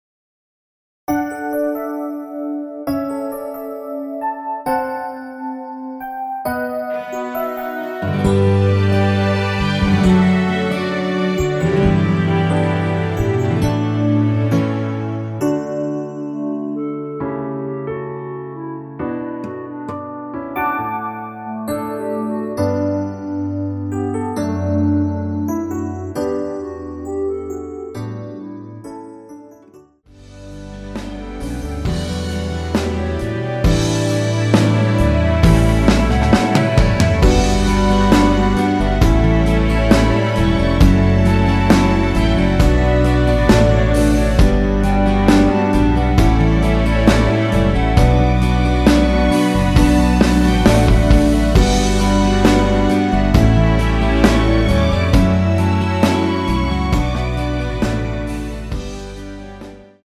원키 멜로디 포함된 MR입니다.
앨범 | O.S.T
앞부분30초, 뒷부분30초씩 편집해서 올려 드리고 있습니다.
중간에 음이 끈어지고 다시 나오는 이유는